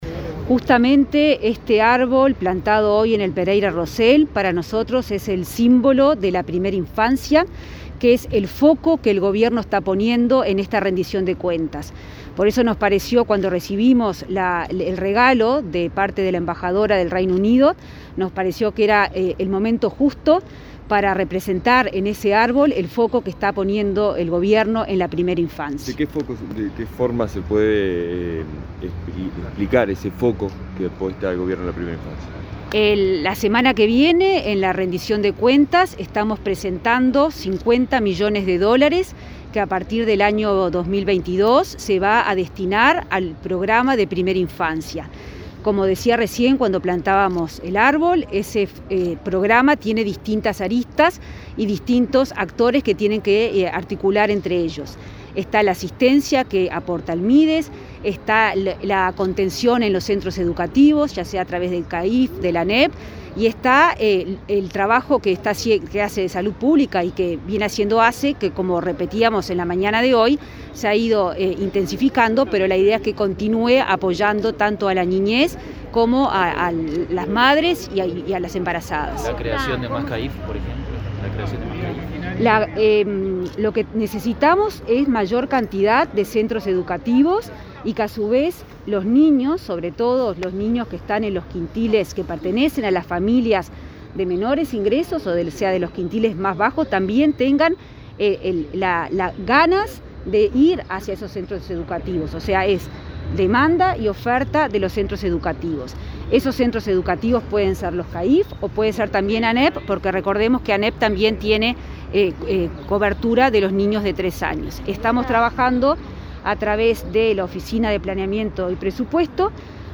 Declaraciones de la ministra Azucena Arbeleche en hospital Pereira Rossell
La ministra de Economía, Azucena Arbeleche, brindó declaraciones a la prensa tras una visita al hospital Pereira Rossell, este viernes 25